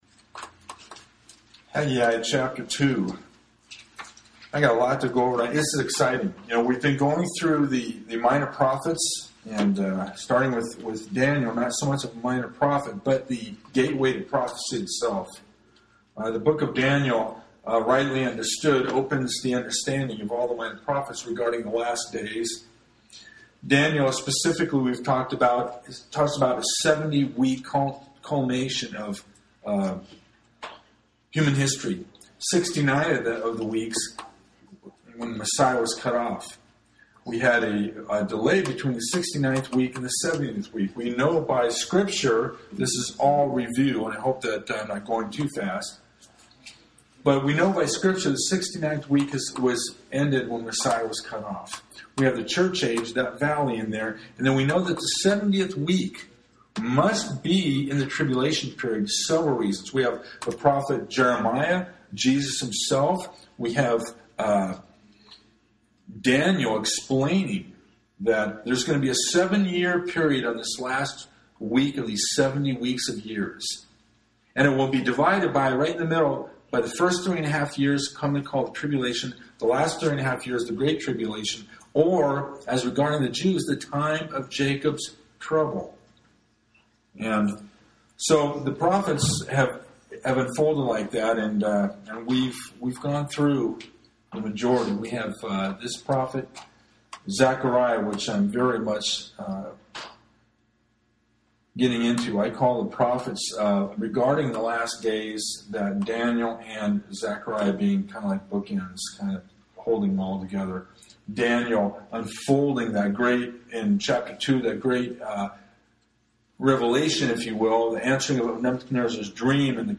Genre: Sermon.